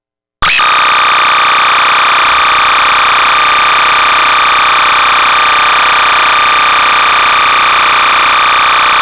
SMPTE Time Code is a sound signal which carries time.
This is a sample of that sound taken from an actual broadcast videotape machine. Hold Your Ears.